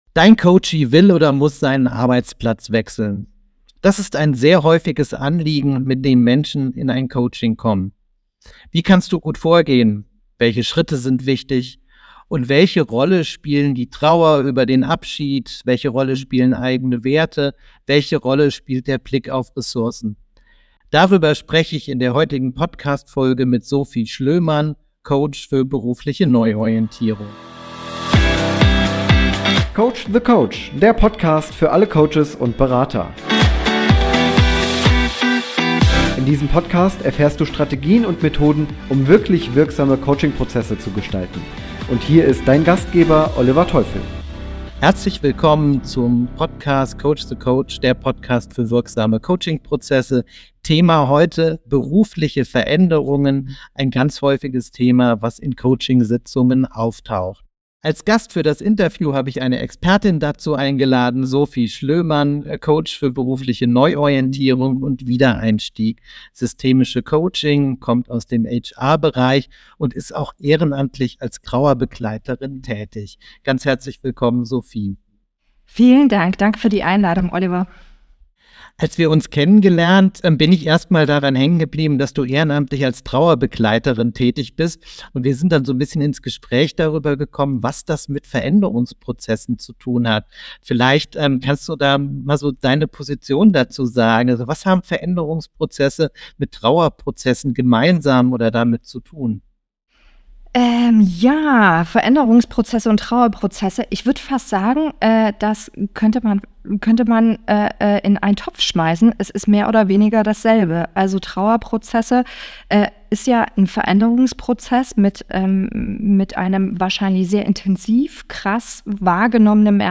Berufliche Neuorientierung coachen - Interview